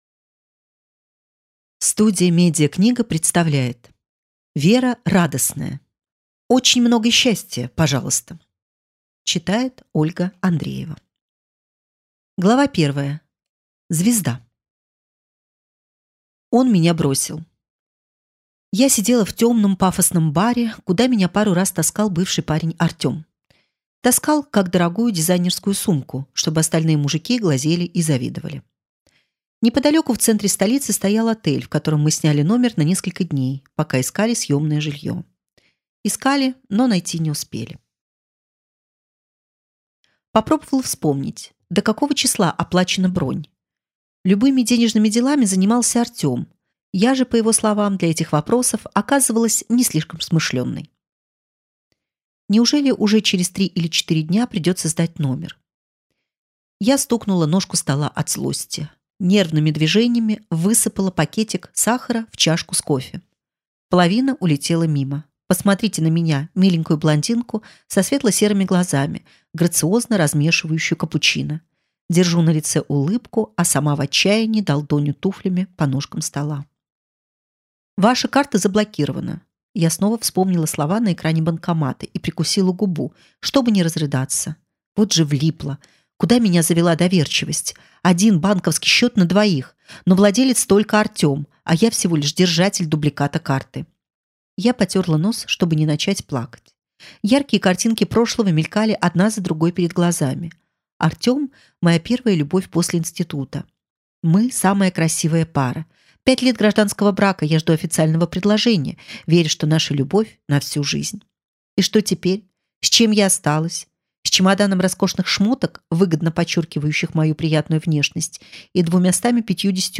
Аудиокнига Очень много счастья, пожалуйста!